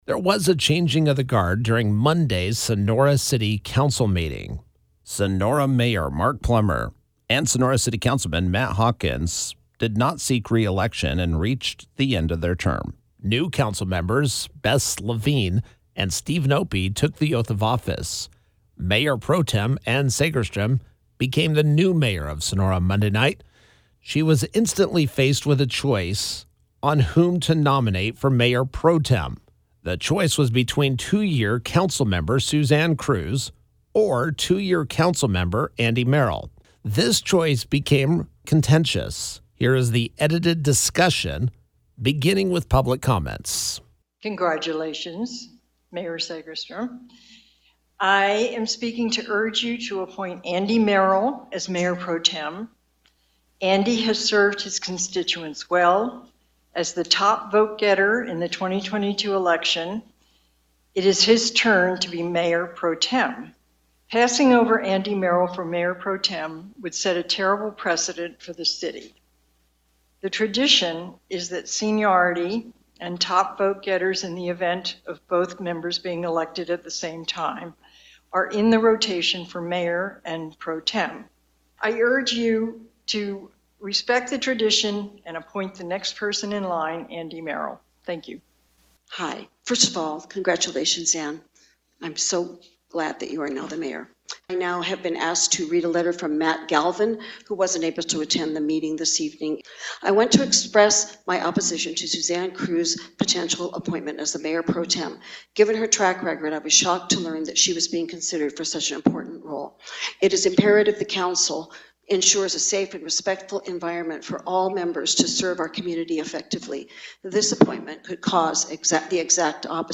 During last Monday’s Sonora City Council Meeting, Sonora Mayor Ann Segerstrom was faced with a choice of nominating either Sonora City Councilman Andy Merrill or Sonora City Councilwoman Suzanne Cruz for Mayor Pro Tem.
Here is the edited audio of that portion of the evening: